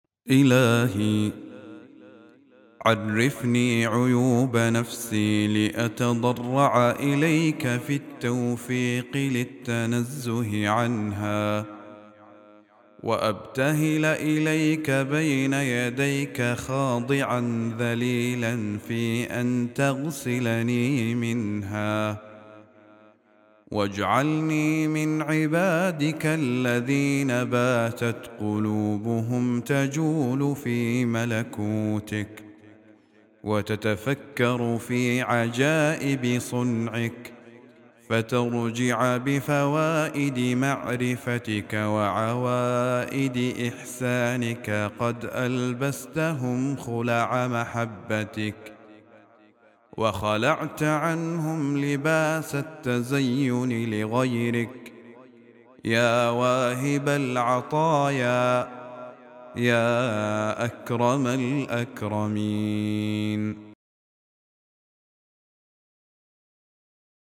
دعاء خاشع يطلب فيه العبد من الله تعالى أن يزكيه ويطهره من عيوب نفسه، ويلحقه بعباده المخلصين الذين يتجولون في ملكوت الله ويتفكرون في عجائب صنعه. يعبر النص عن شوق عميق للأنس بالله وارتداء ثوب محبته.